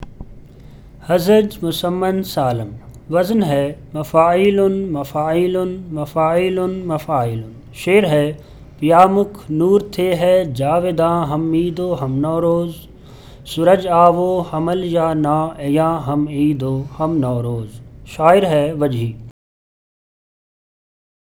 Audio samples of the recitation of the Urdu metre